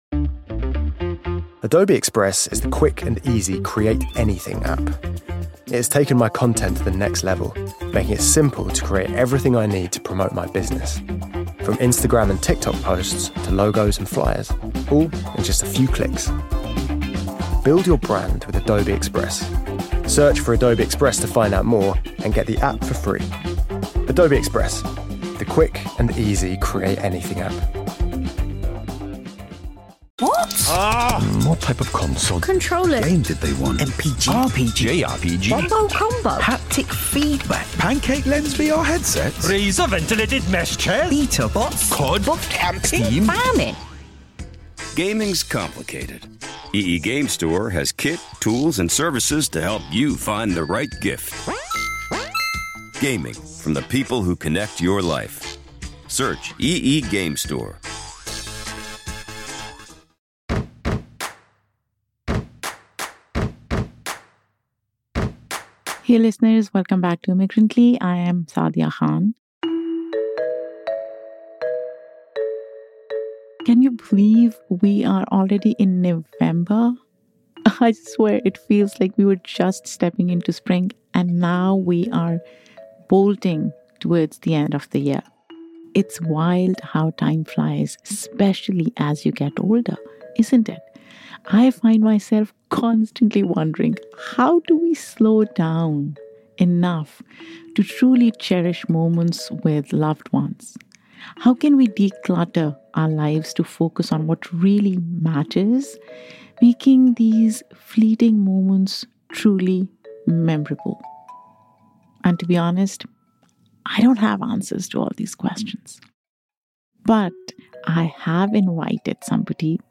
Get ready for a conversation full of warmth, insight, and inspiration. Tune in, slow down with us, and explore how to reclaim presence in a world that rarely pauses.